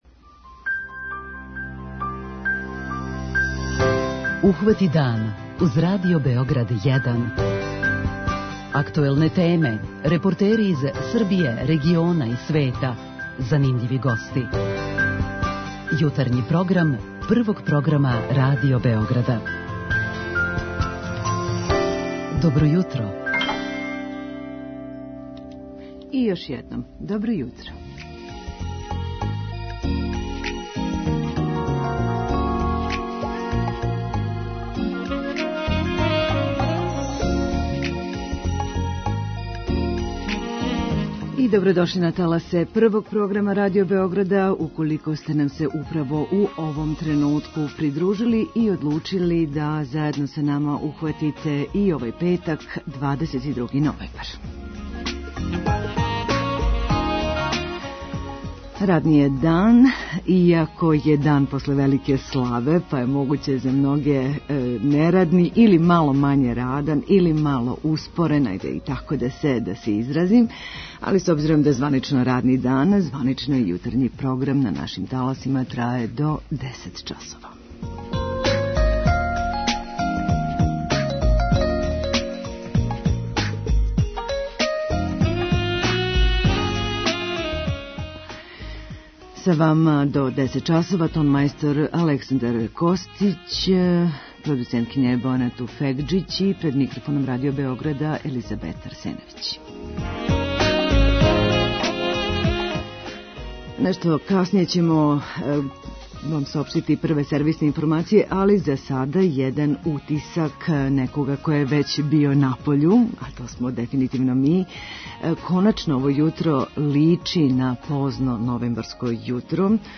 Репортера ћемо имати и на Београдском сајму на којем почиње 8. Међународни сајам спорта.